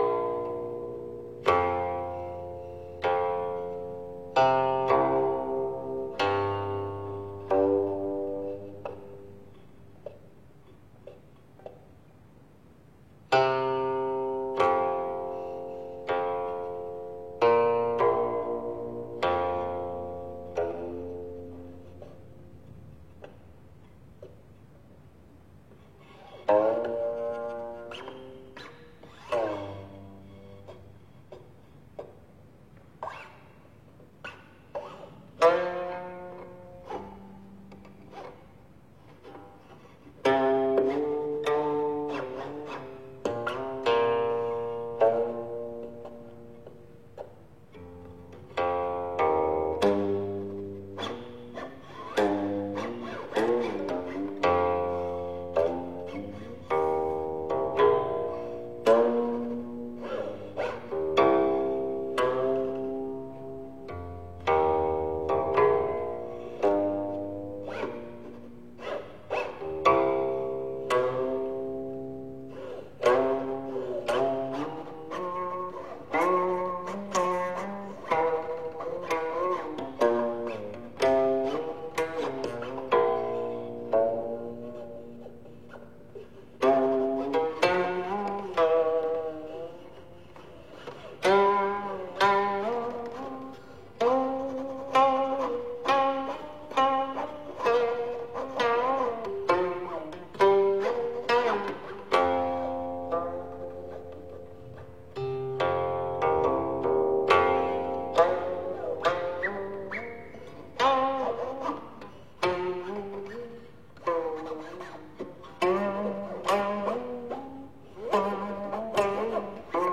渔樵问答--古琴
渔樵问答--古琴 冥想 渔樵问答--古琴 点我： 标签: 佛音 冥想 佛教音乐 返回列表 上一篇： 天风环佩--古琴 下一篇： 雨碎江南--古琴 相关文章 出塞--未知 出塞--未知...